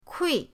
kui4.mp3